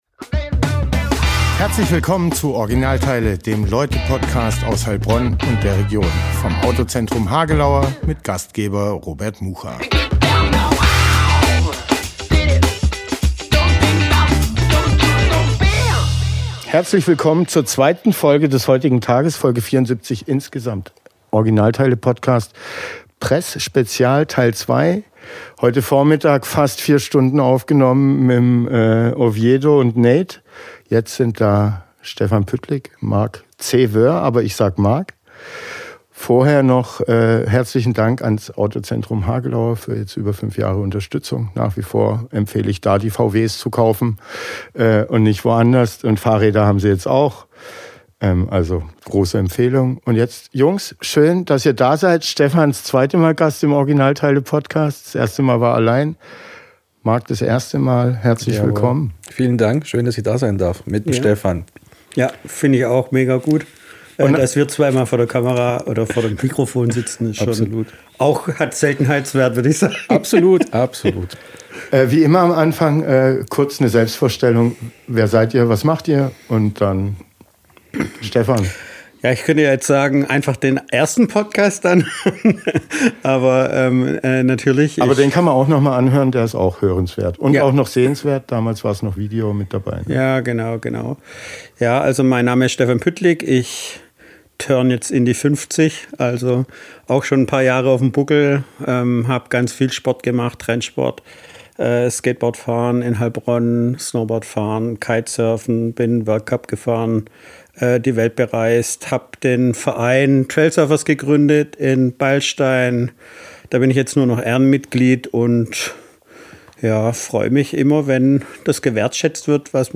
Talk
Interview